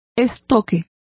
Complete with pronunciation of the translation of rapier.